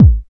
34 KICK 3.wav